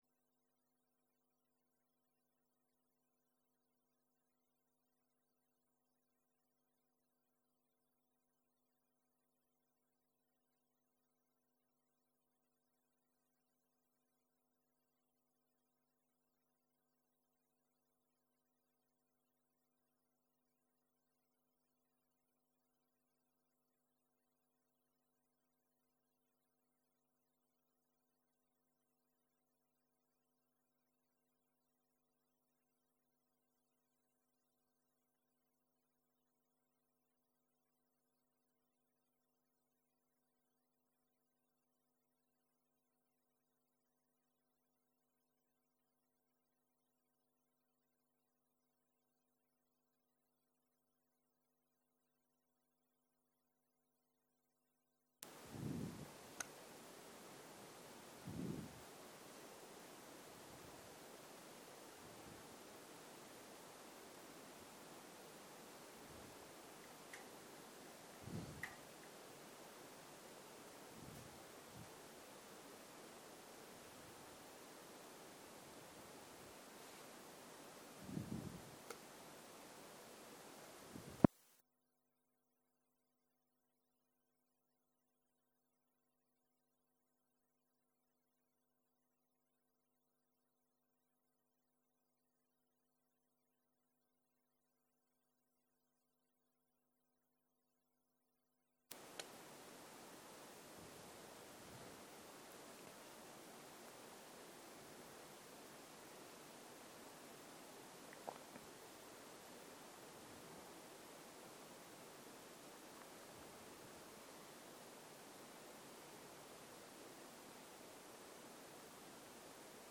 צהריים - מדיטציה מונחית
Guided meditation